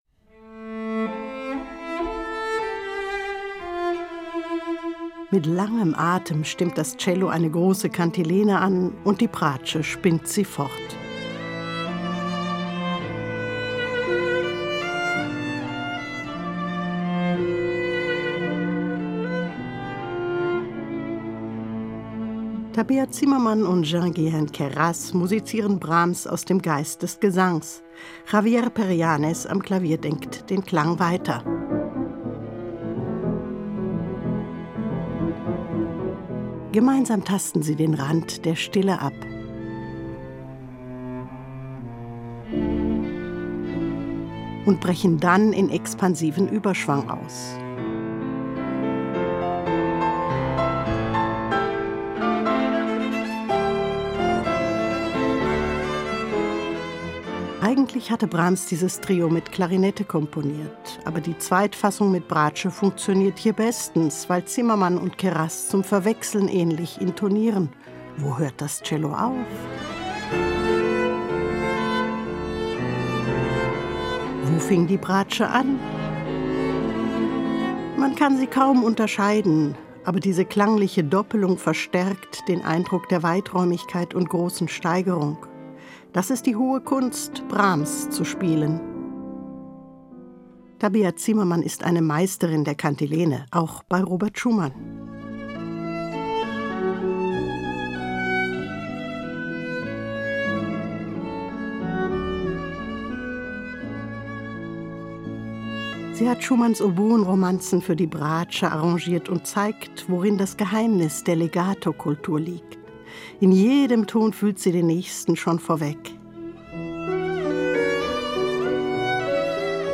Album-Tipp